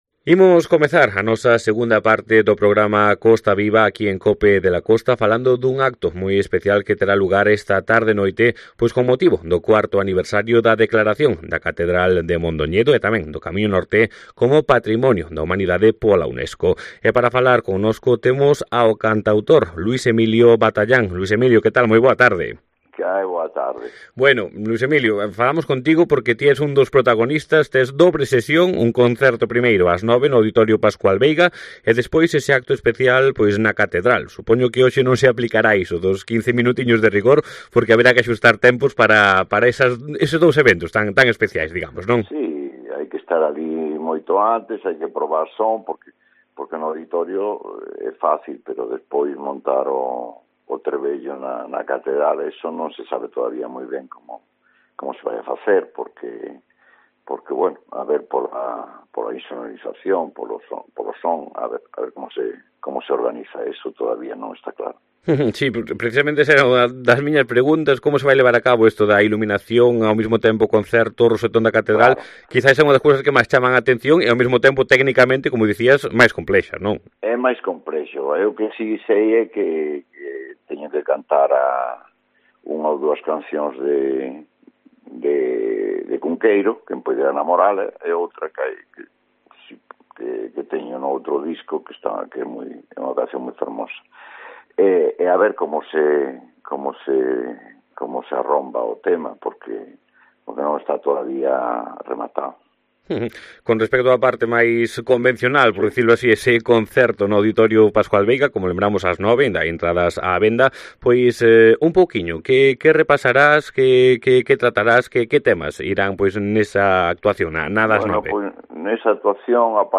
entrevistado en COPE de la Costa